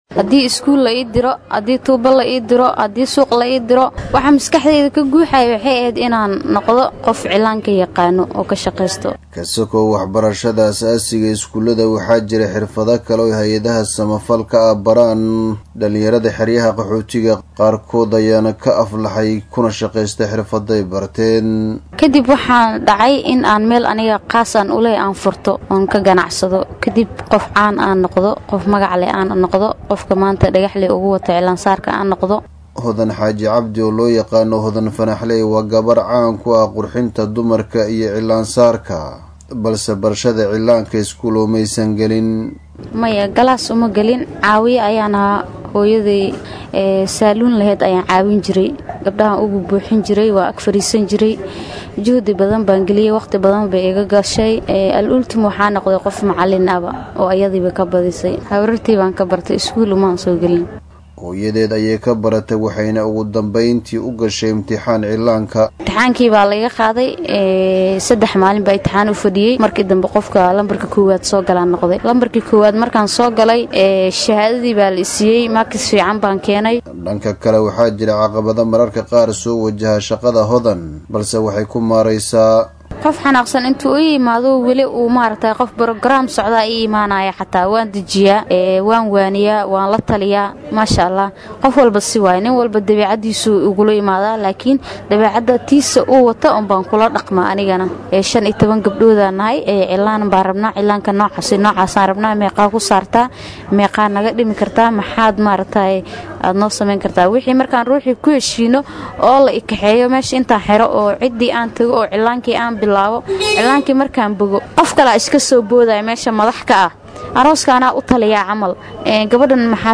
warbixintan Dadaab ka soo diray.